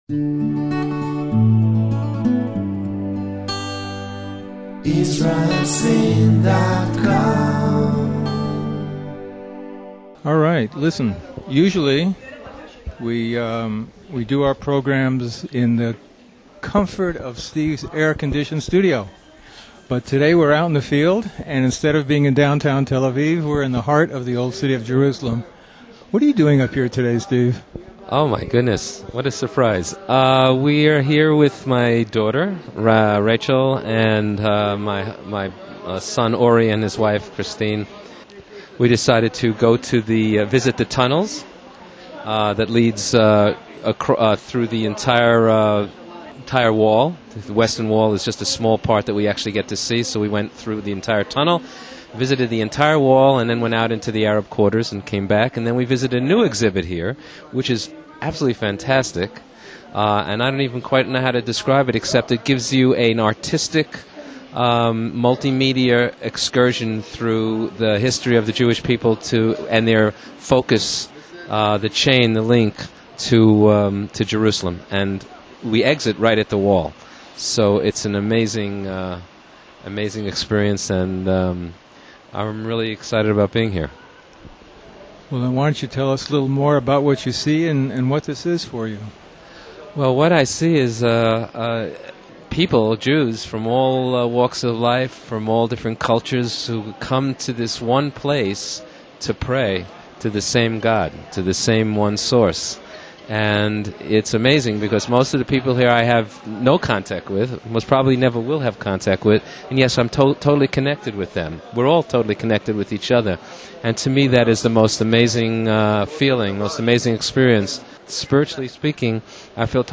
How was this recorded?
at the Wall in Jerusalem